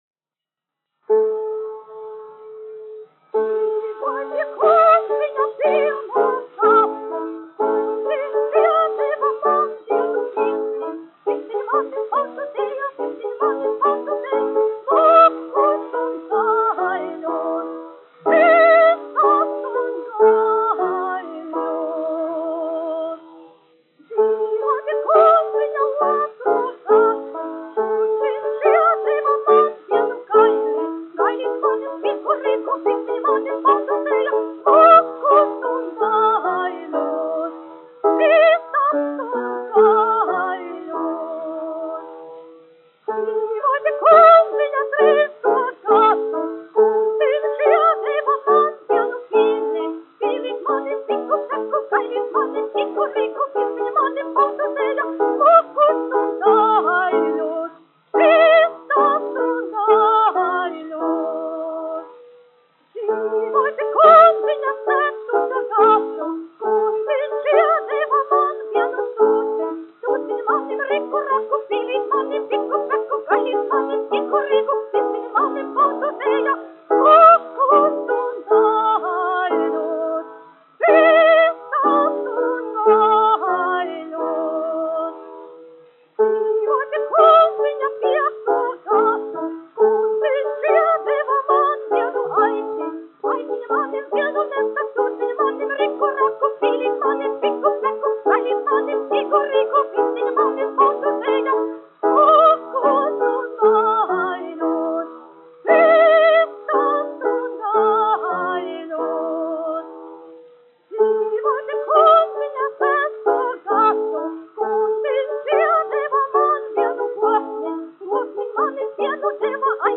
1 skpl. : analogs, 78 apgr/min, mono ; 25 cm
Latviešu tautasdziesmas
Skaņuplate
Latvijas vēsturiskie šellaka skaņuplašu ieraksti (Kolekcija)